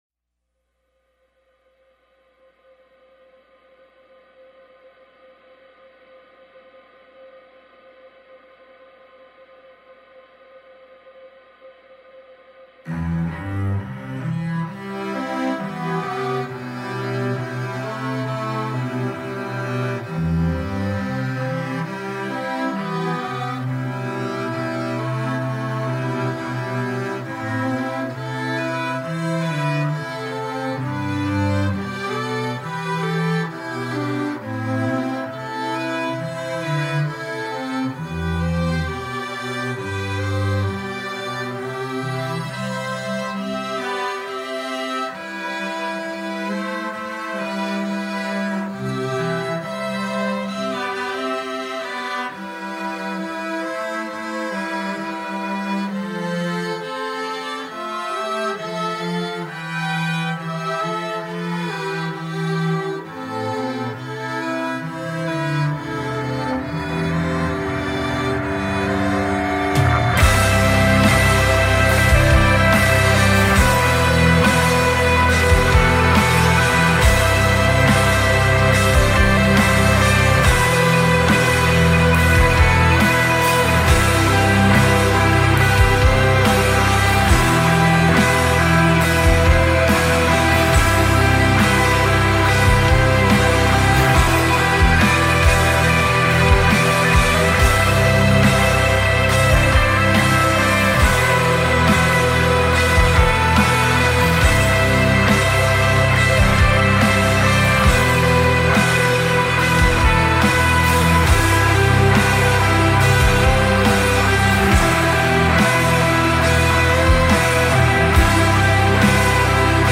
five-piece skygaze band
increasingly complex arrangements and instrumentation